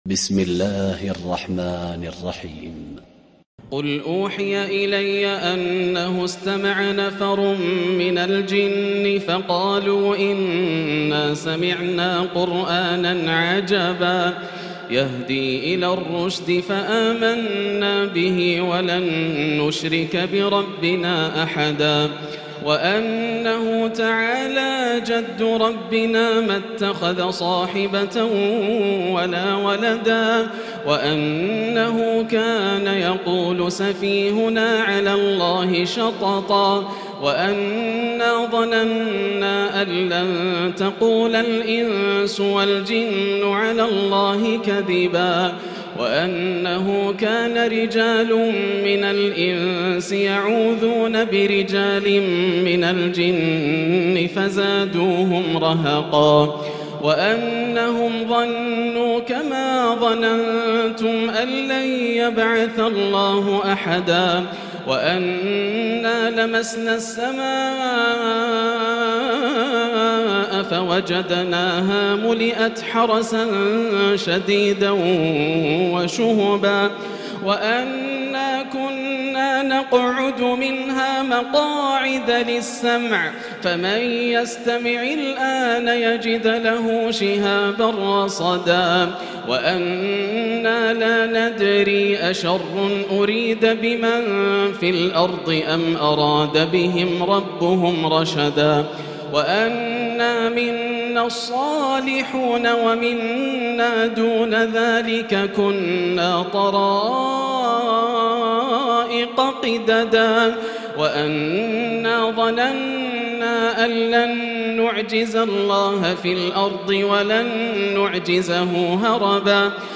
الليلة الثامنة والعشرون - من سورة الجن حتى سورة المرسلات > الليالي الكاملة > رمضان 1438هـ > التراويح - تلاوات ياسر الدوسري